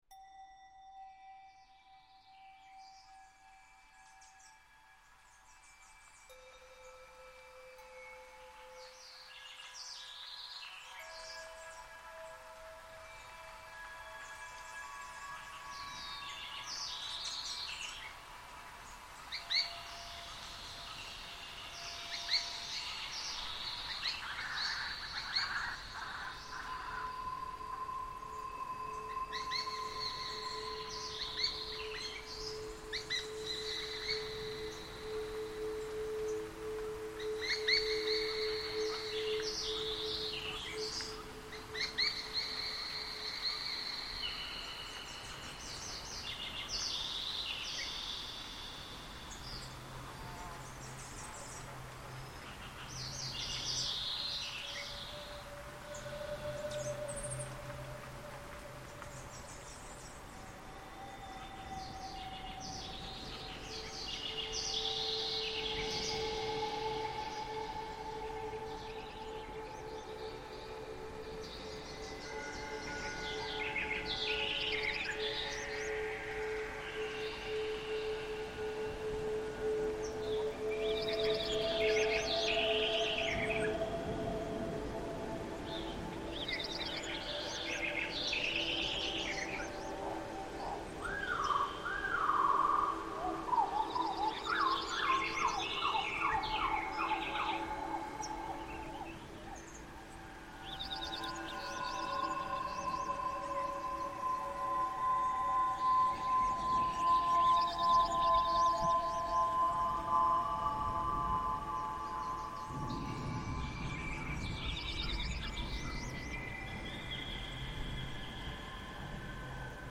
Dawn at Machu Picchu reimagined